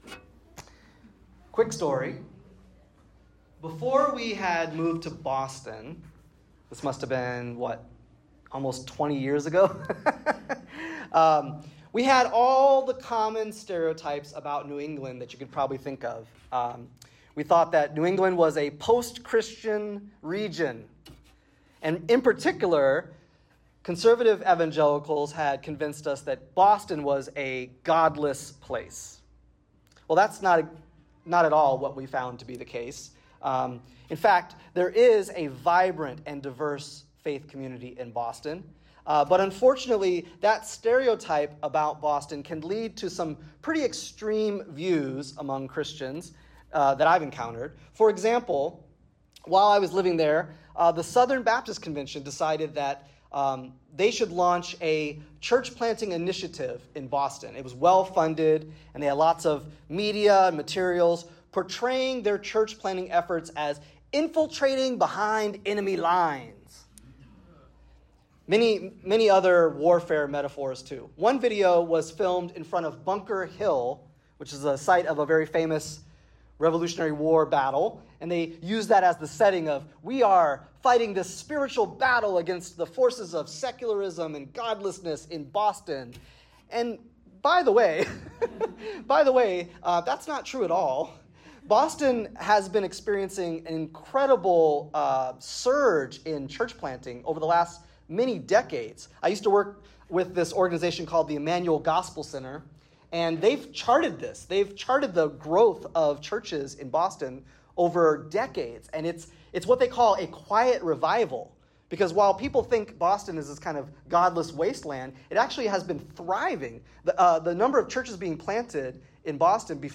This week’s sermon continued our exploration of Jesus’s Sermon on the Mount, focusing on the metaphor of being the “light of the world” and a “city on a hill” (Matthew 5:14).